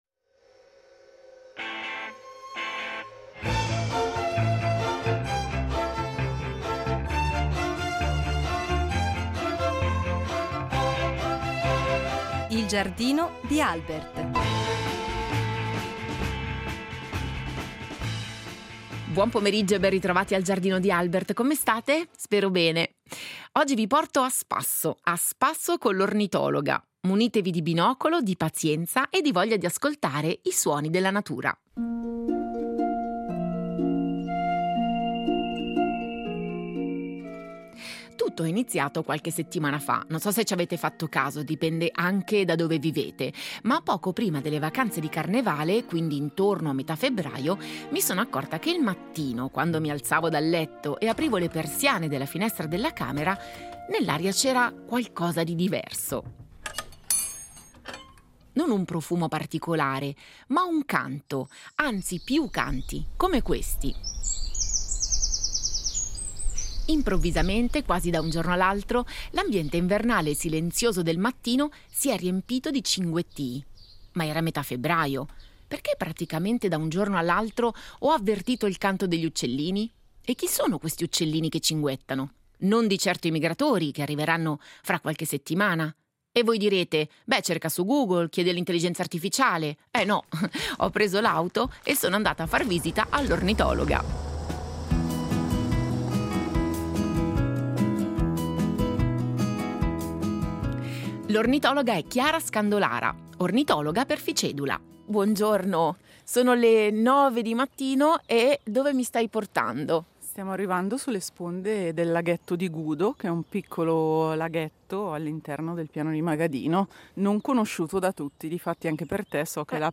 Bastano pochi attimi e la natura non tarda a dare spettacolo: un airone cenerino sorvola il laghetto, un picchio muratore richiama da un tronco, quattro germani reali inscenano una danza sull’acqua, mentre un martin pescatore si tuffa nell’acqua alla ricerca di prede. In questa puntata vi raccontiamo il significato dei canti, delle prime “faide amorose” e dei delicati equilibri che regolano la vita degli uccelli in questa fase dell’anno.